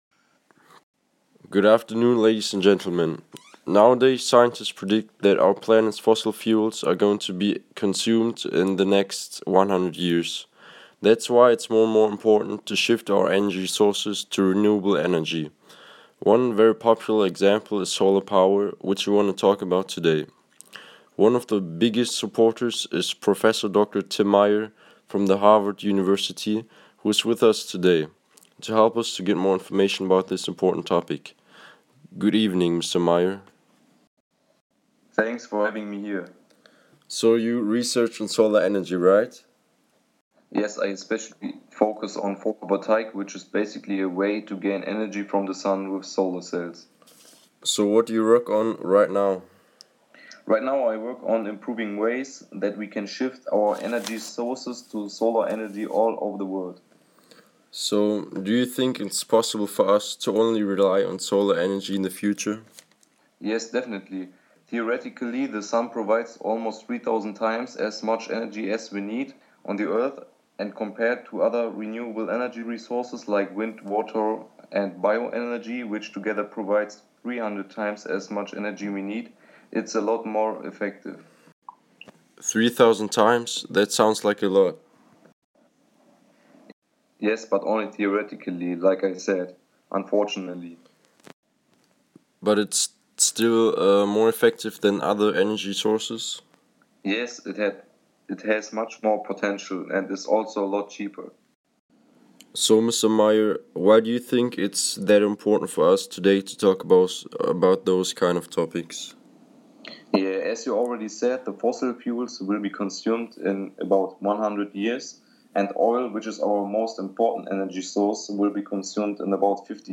Solar energy interview